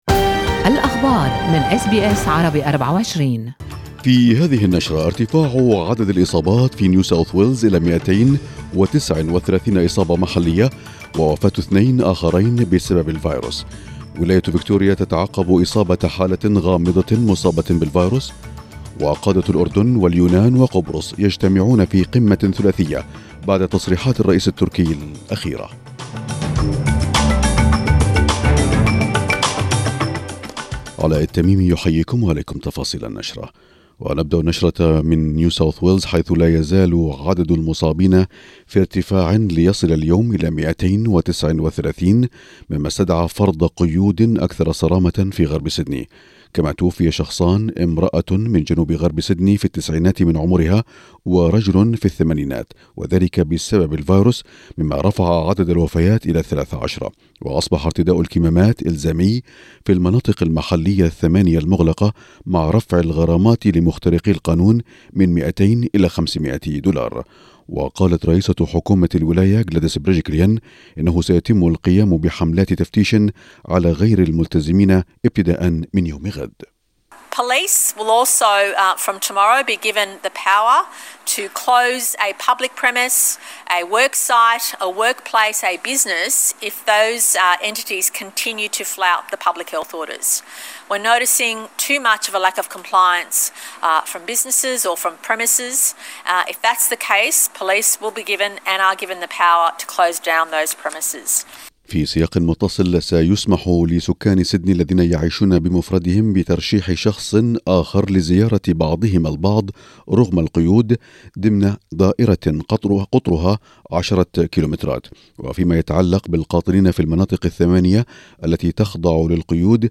نشرة أخبار المساء 29/7/2021